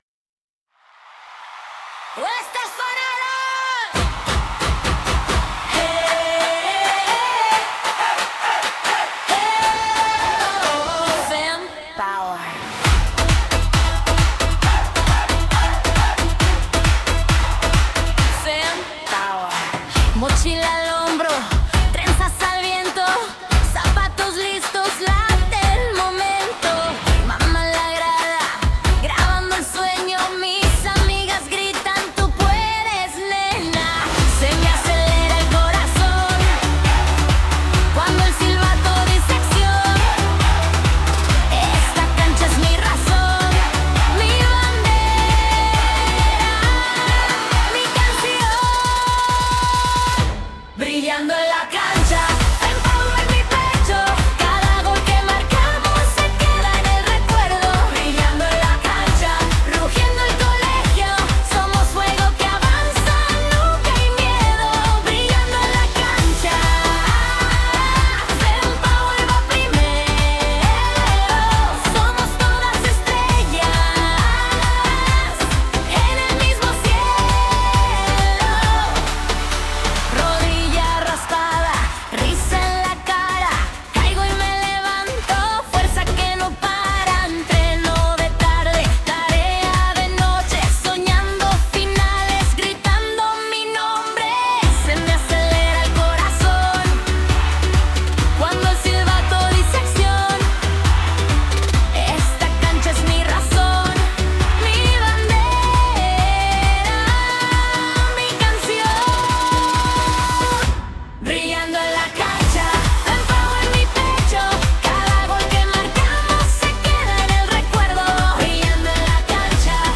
El himno de FemPower Cup